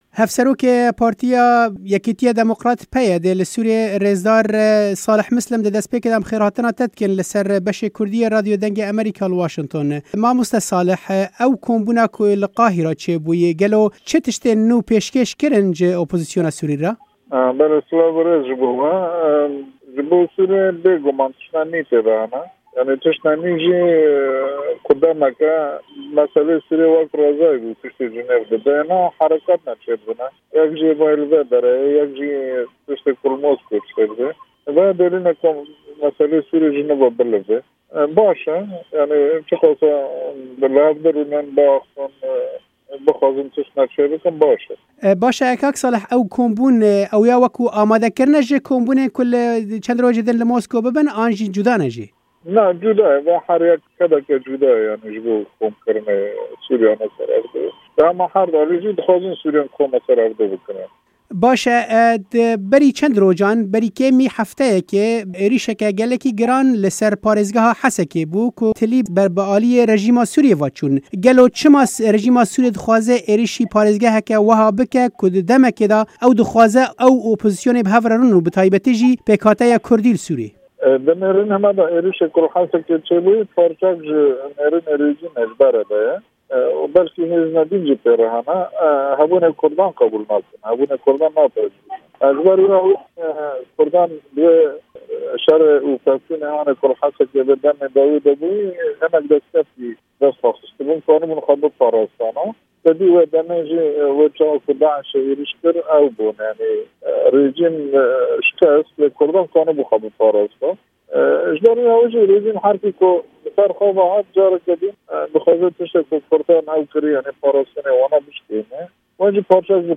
وتووێژی ساڵح موسلیم